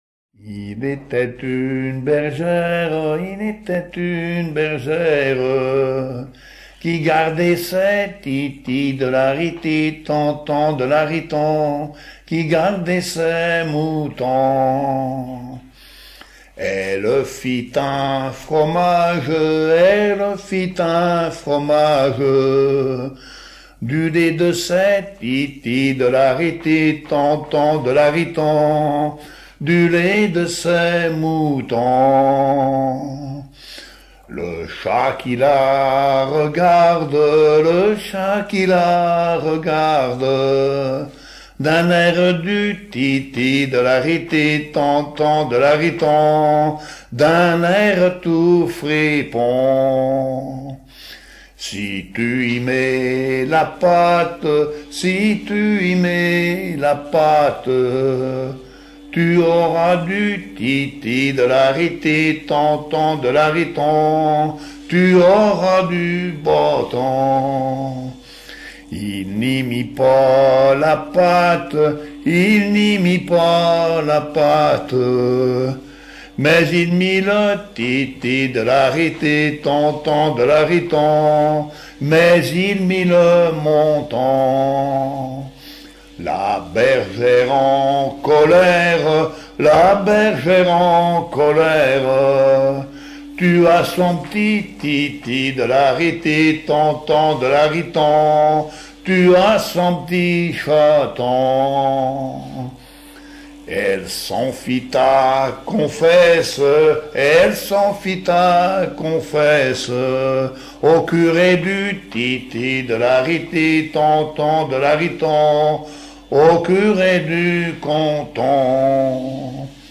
Localisation Saint-Michel-Mont-Mercure
Genre laisse